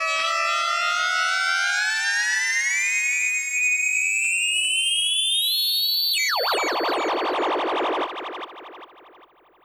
45 SYNTH 3-L.wav